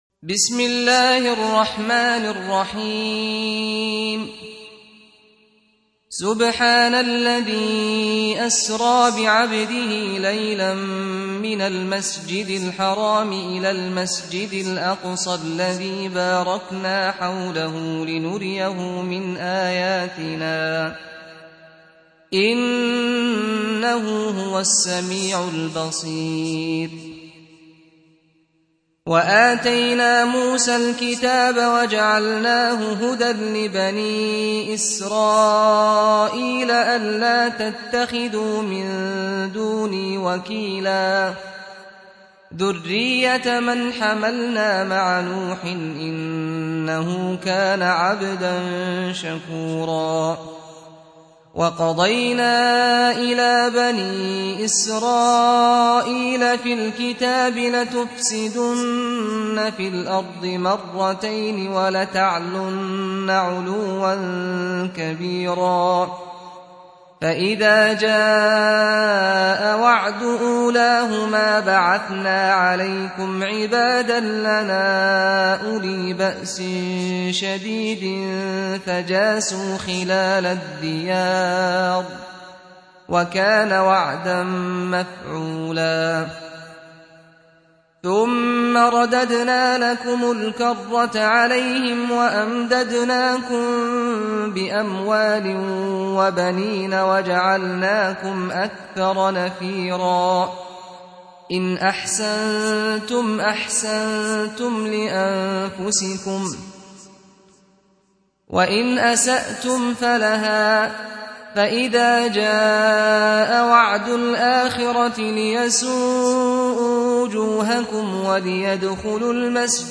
17. Surah Al-Isr�' سورة الإسراء Audio Quran Tarteel Recitation
Surah Repeating تكرار السورة Download Surah حمّل السورة Reciting Murattalah Audio for 17.